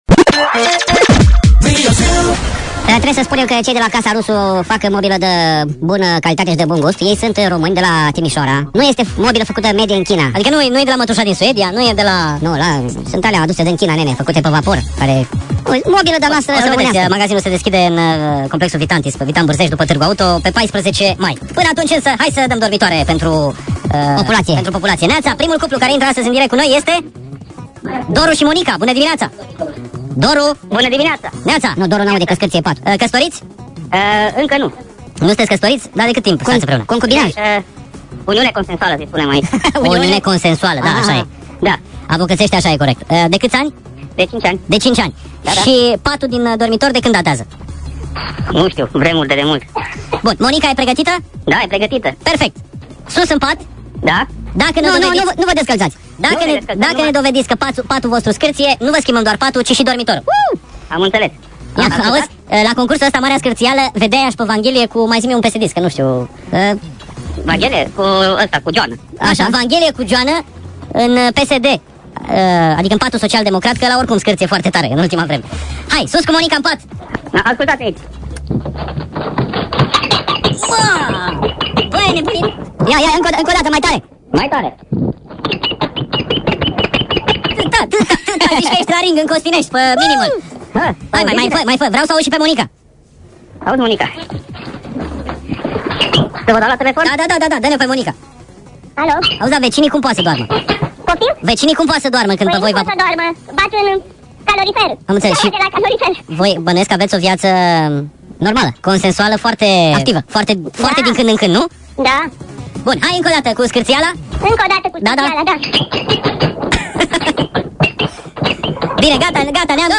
"Marea Scartziala" remix - Chipmunks version